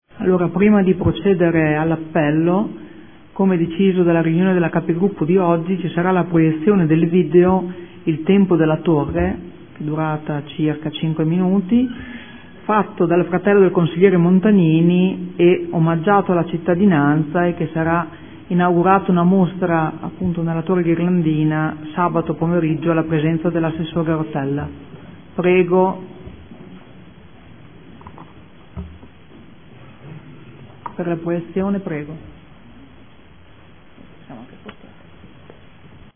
Seduta del 11/09/2014 Presentazione del video "Il tempo della Torre"